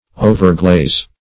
Overglaze \O"ver*glaze`\, a.